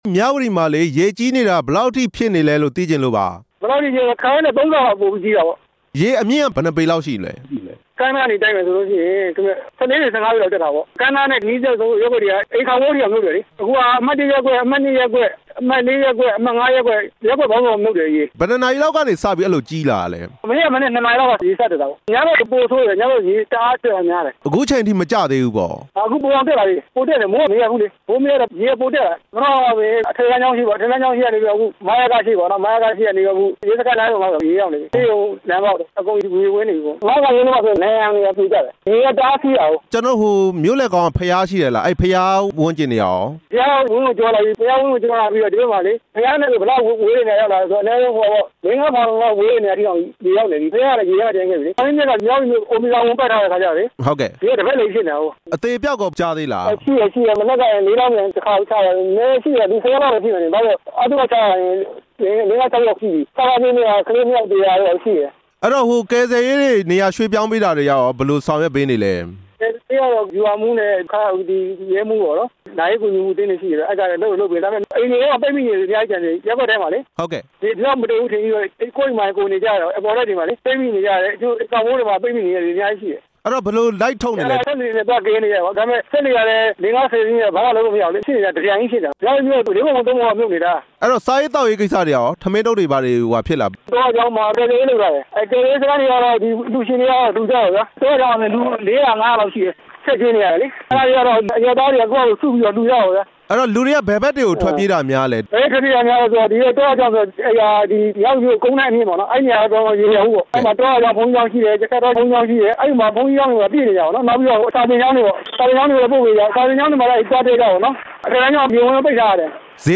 မြဝတီမြို့ရေဘေး နောက်ဆုံးအခြေအနေ တင်ပြချက်